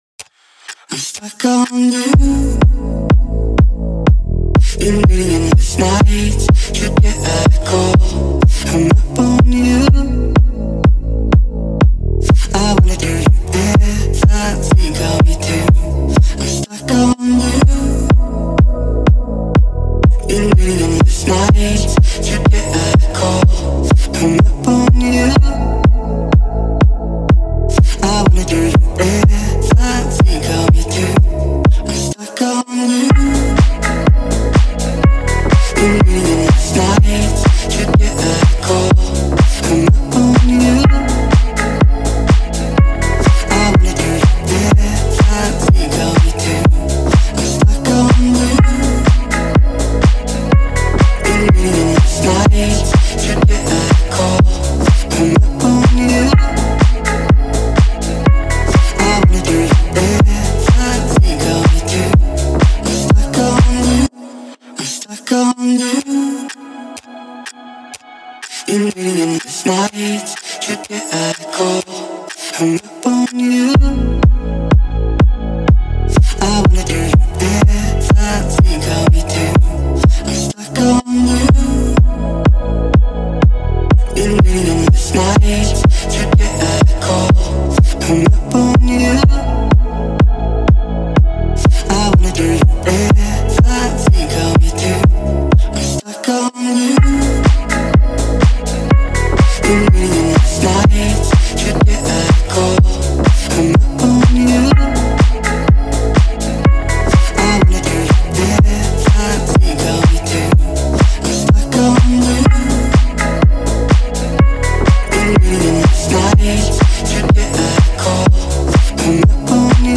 это зажигательная трек в жанре поп с элементами EDM